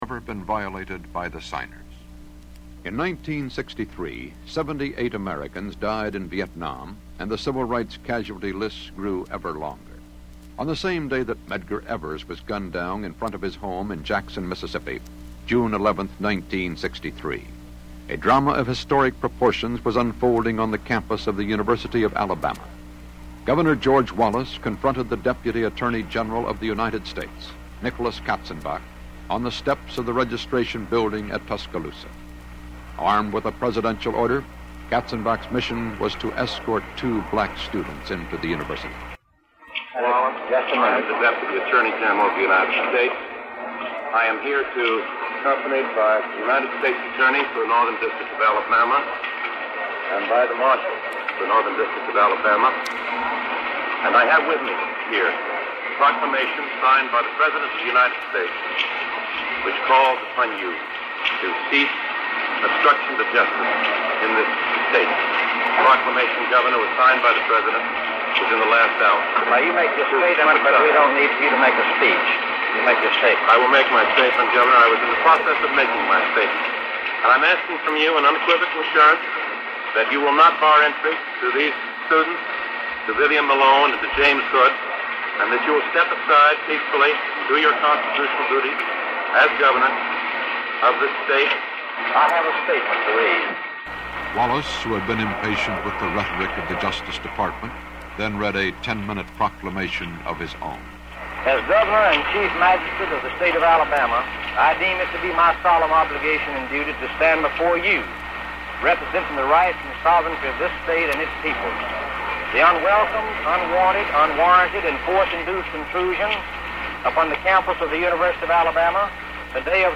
Recall some of those turbulent times by listening to Walter Cronkite’s narration of the confrontation with
cronkite-wallace-king.mp3